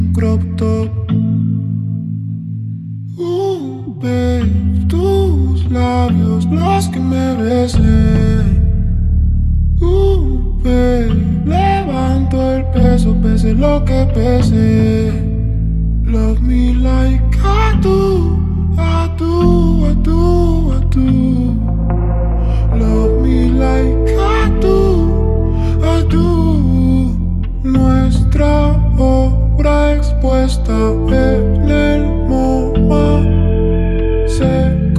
Pop Latino Latin